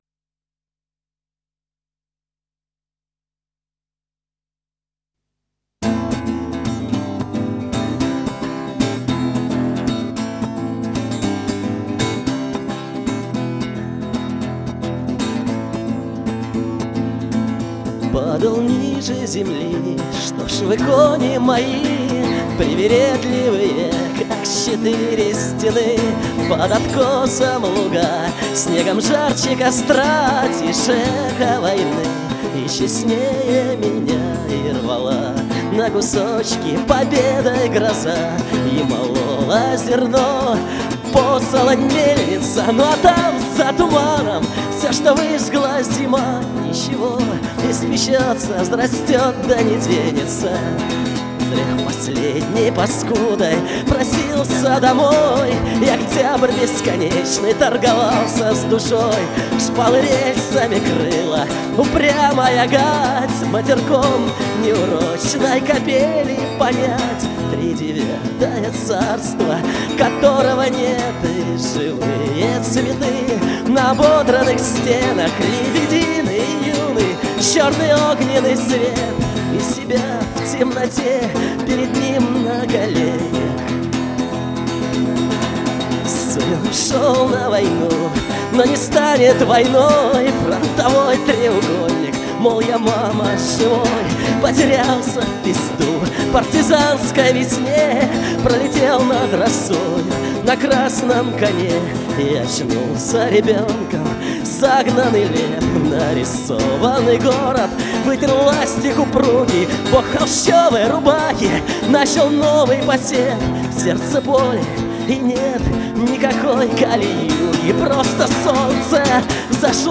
Запись со студийного диска.